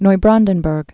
(noi-brändən-bûrg, -brk)